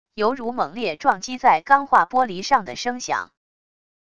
犹如猛烈撞击在钢化玻璃上的声响wav音频